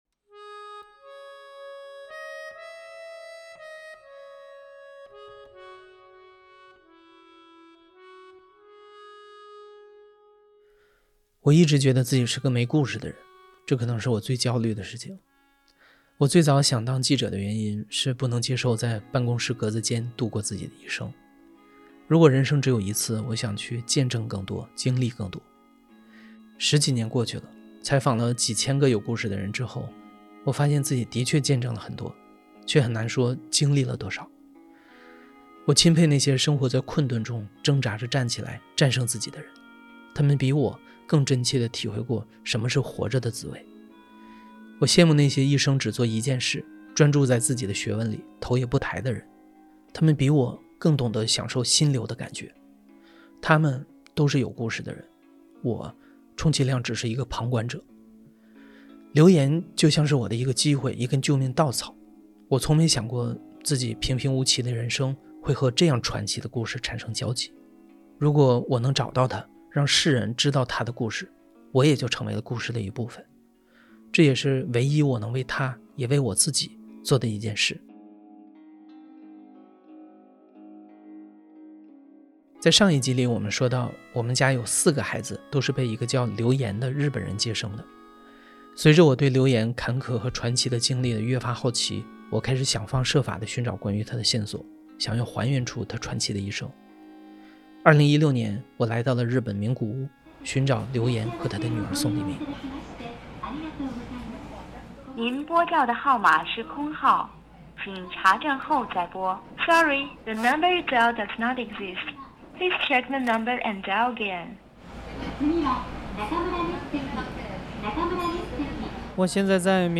故事FM ❜ 2025年度大型系列声音纪录片 我一直觉得自己是个没故事的人，这可能是我最焦虑的事情。
故事FM 是一档亲历者自述的声音节目。